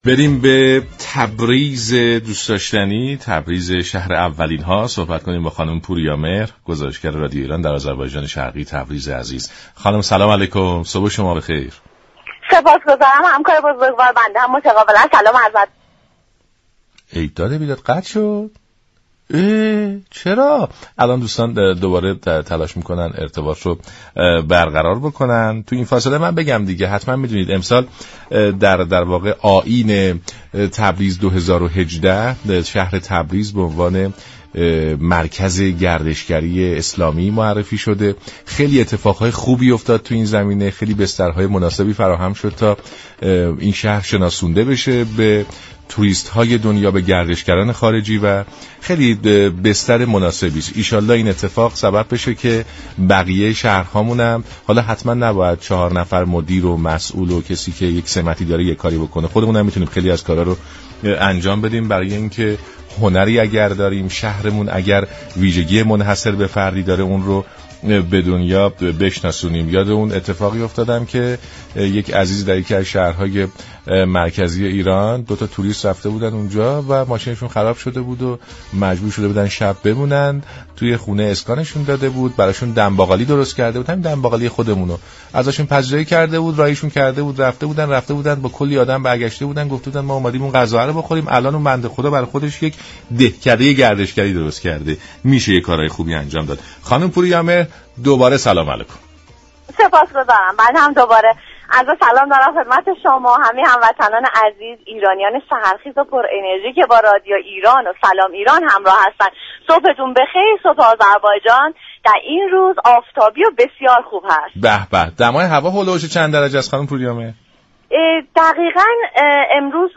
در ارتباط تلفنی با برنامه «سلام ایران»